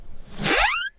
CabinetClose.ogg